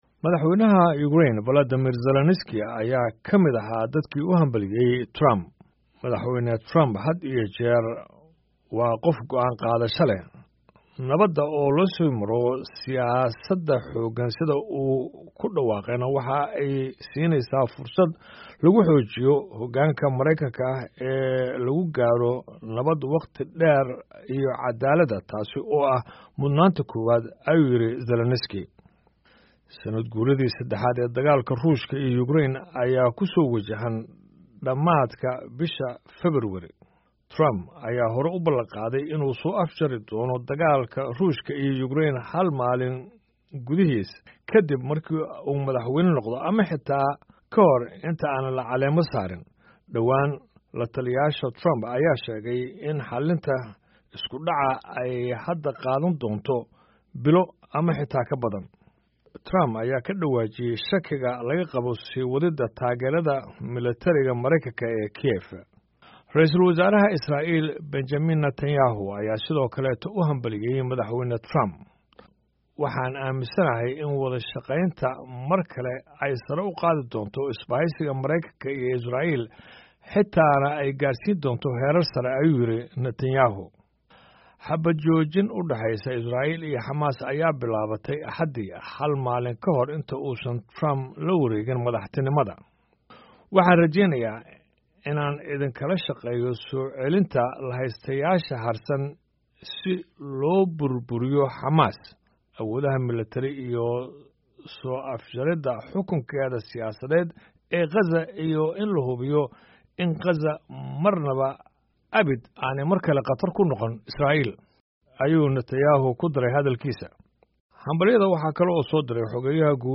Hogaamiyayaasha waxa ka mid ahaa kuwa Ukraine, Israel iyo Jarmalka.